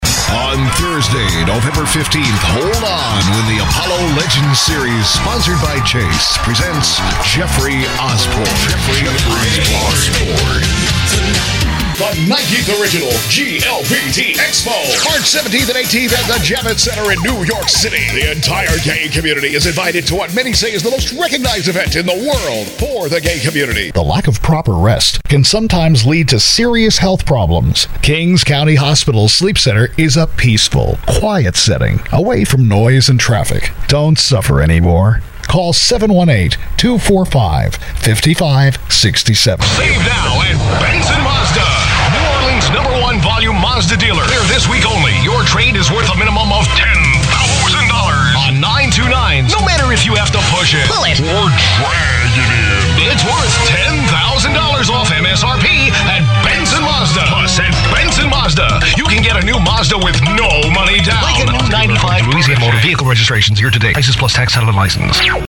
VOICEOVER DEMOS
Commercial Demo
Broadcast-quality home studio with digital delivery; ISDN with 24-hour notice.